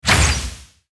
archer_skill_triangleshot_02_fire.ogg